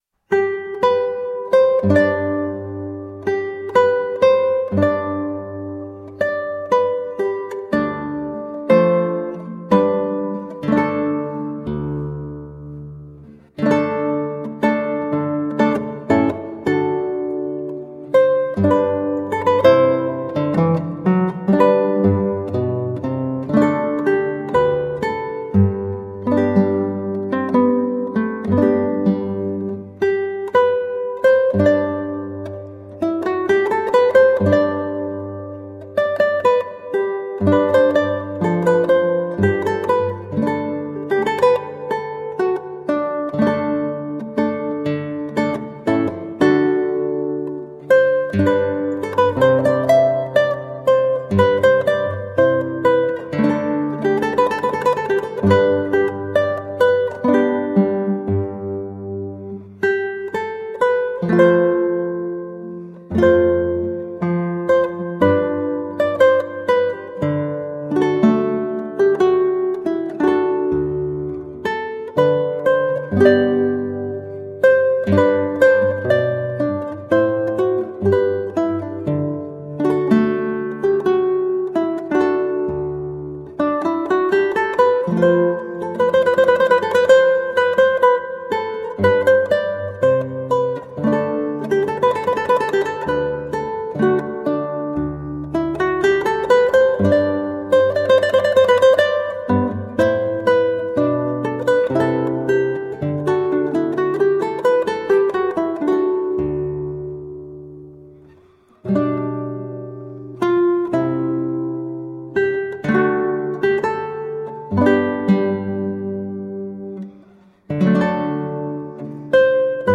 Colorful classical guitar.
played on 8-string guitar